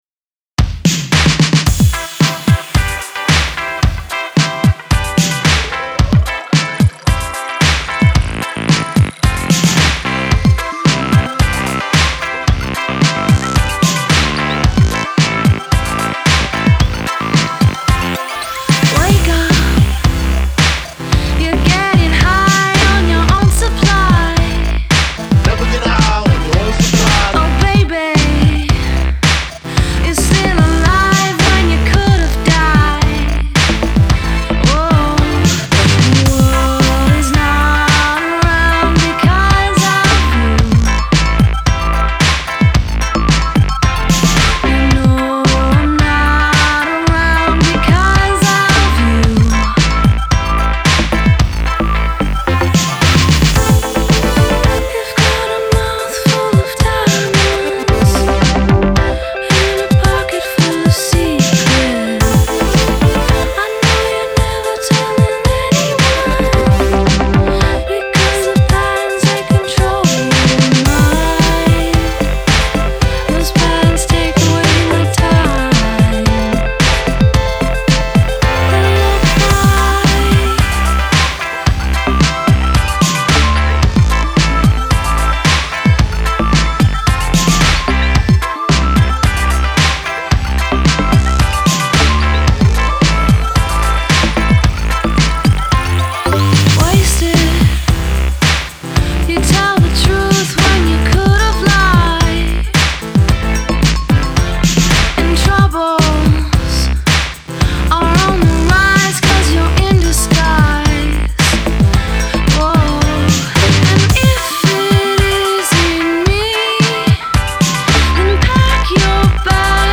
replaced it with 80’s synthpop